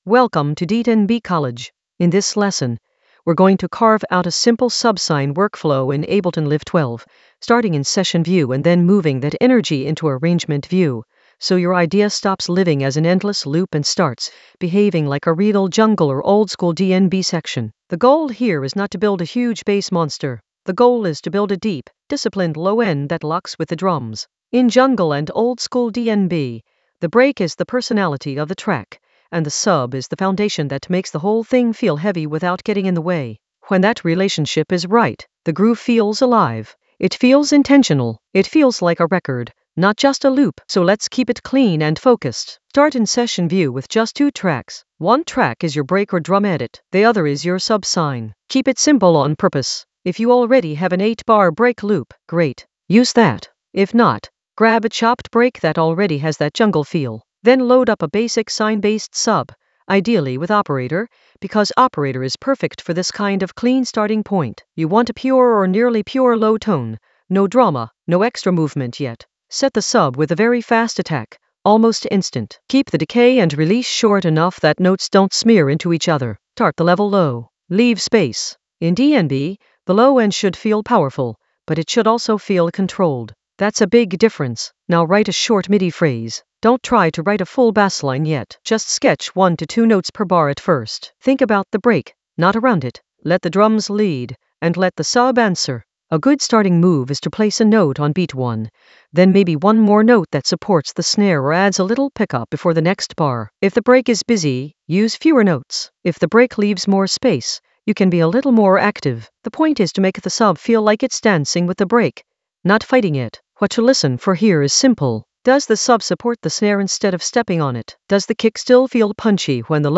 An AI-generated beginner Ableton lesson focused on Carve a subsine workflow using Session View to Arrangement View in Ableton Live 12 for jungle oldskool DnB vibes in the Drums area of drum and bass production.
Narrated lesson audio
The voice track includes the tutorial plus extra teacher commentary.